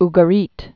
(gə-rēt)